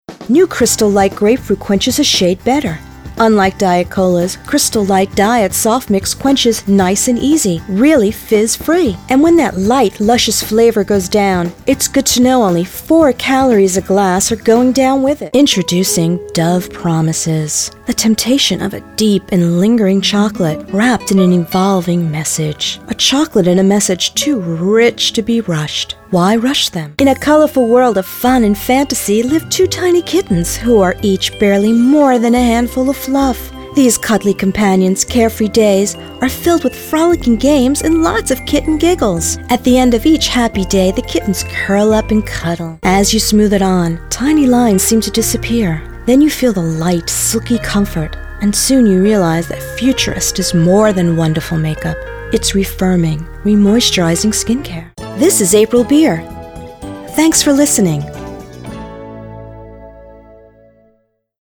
Female Voice-Over Talent
Commercial Demo:
NOTE: These files are high quality stereo audio files, therefore they are large in size.